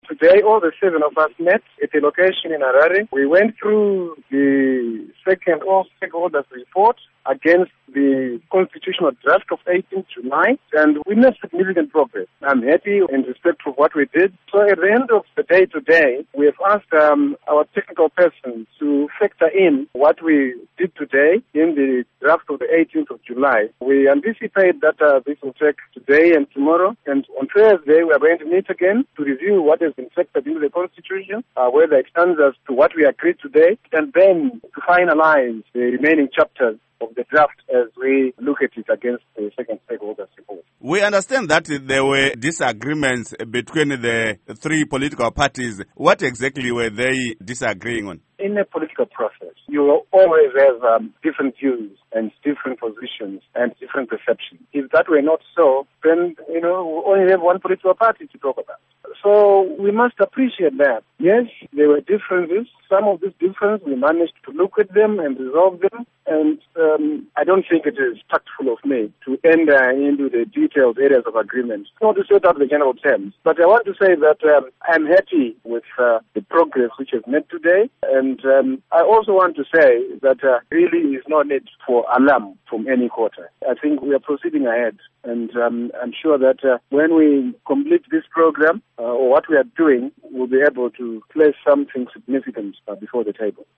Interview With Minister Eric Matinenga